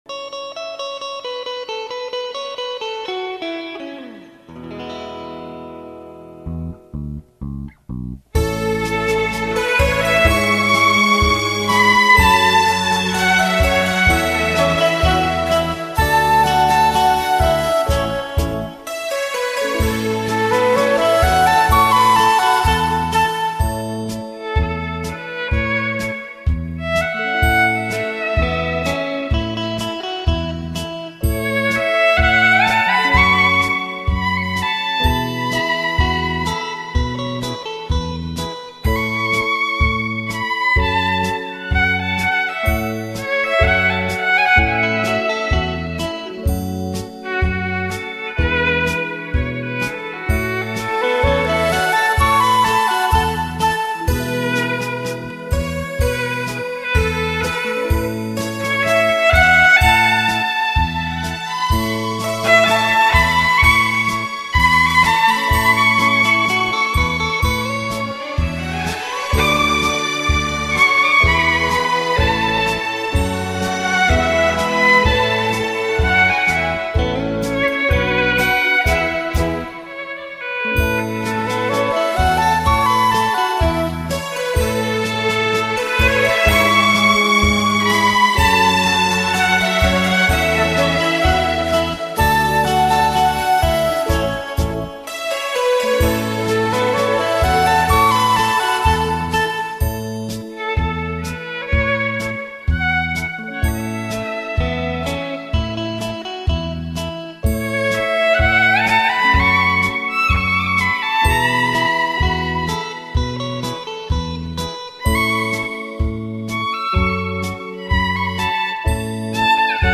경음악
바이올린 연주곡